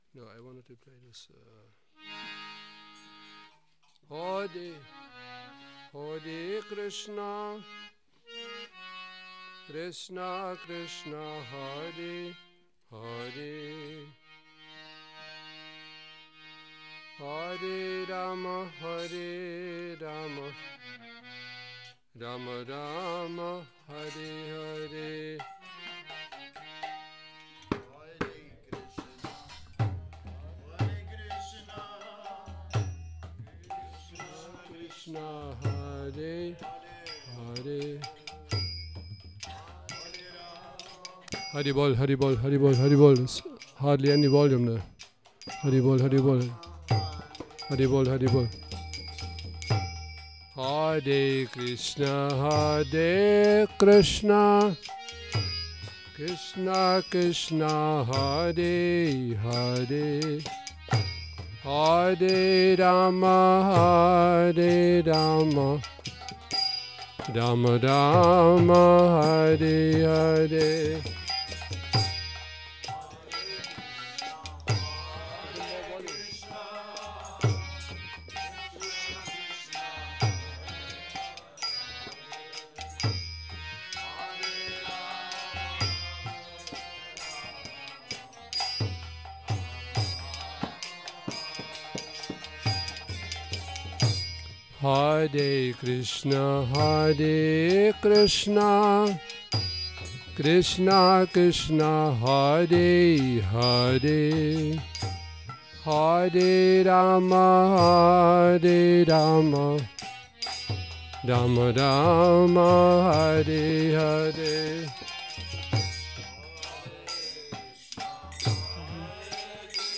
Kírtan Nedělní program